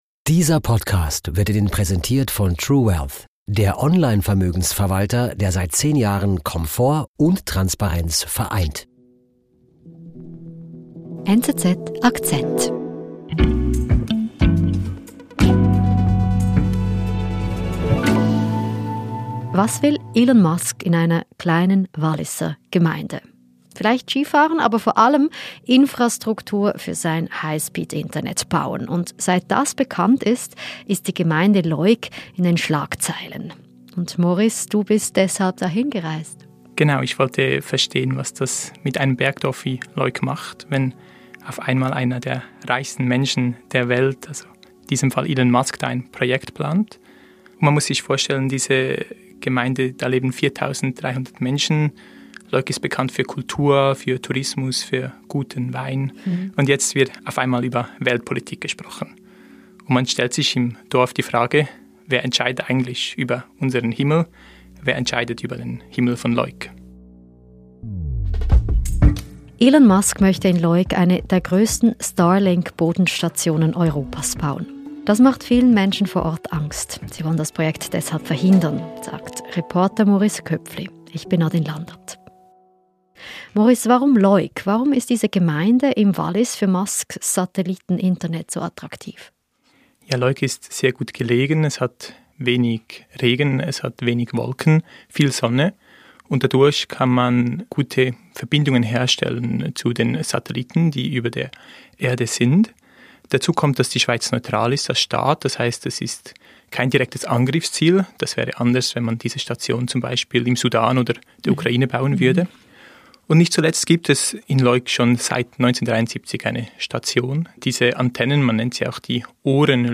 Eine Reportage.